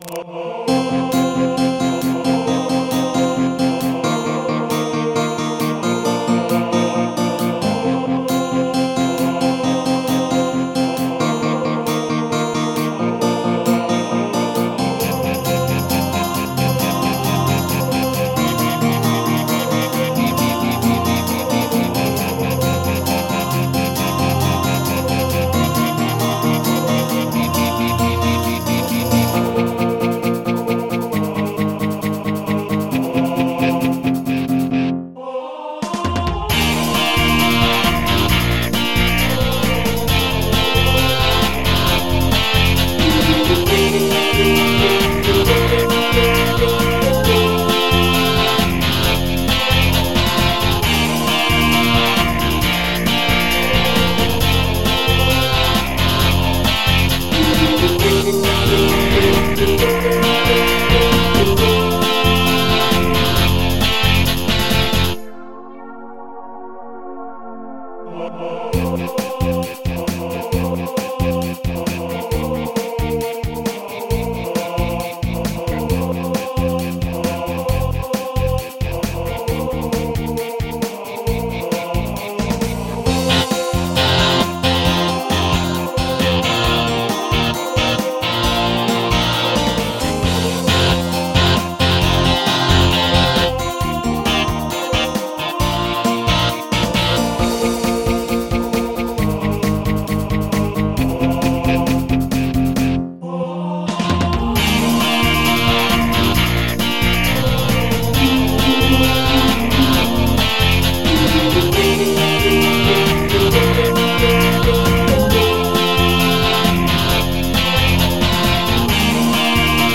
MIDI 72.46 KB MP3 (Converted)